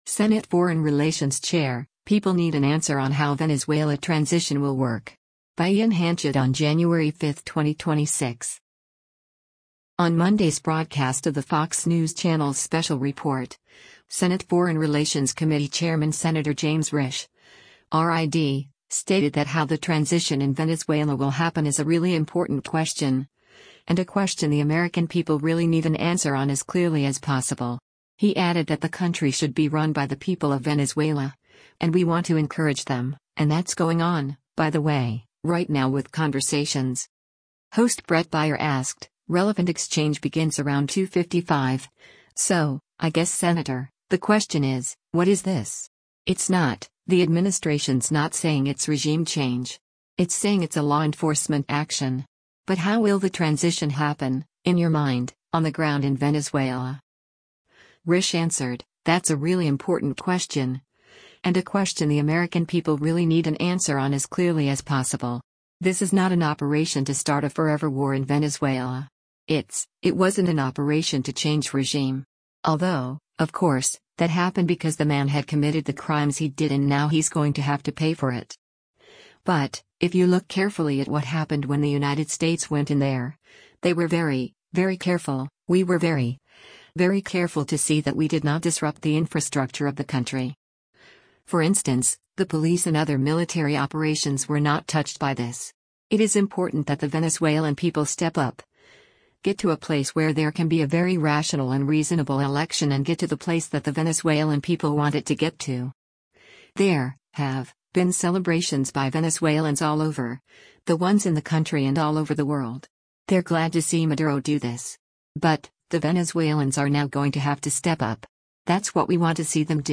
On Monday’s broadcast of the Fox News Channel’s “Special Report,” Senate Foreign Relations Committee Chairman Sen. James Risch (R-ID) stated that how the transition in Venezuela will happen is “a really important question, and a question the American people really need an answer on as clearly as possible.” He added that the country should be run by the people of Venezuela, and “We want to encourage them, and that’s going on, by the way, right now with conversations.”